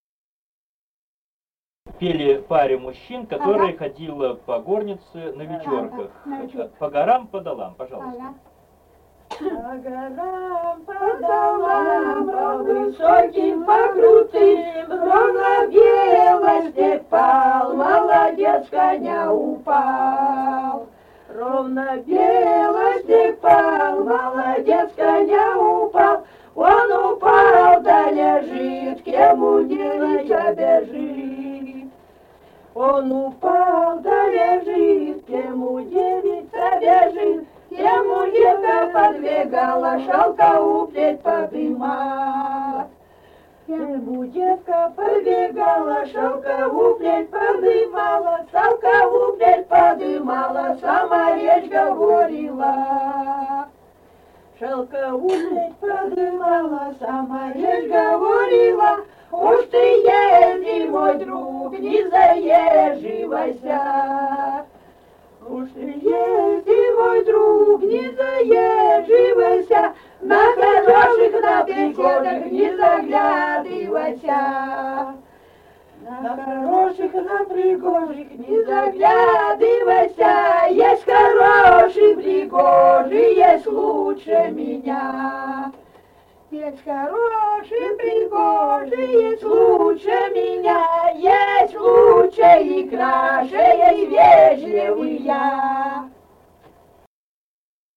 Русские песни Алтайского Беловодья 2 «По горам, по долам», вечёрочная.
Республика Казахстан, Восточно-Казахстанская обл., Катон-Карагайский р-н, с. Урыль (казаки), июль 1978.